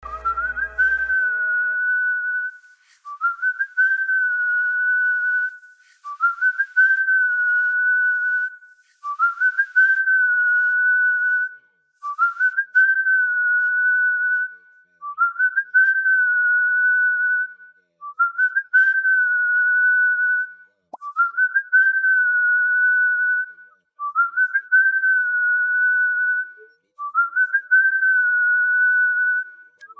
catchy melody and unique style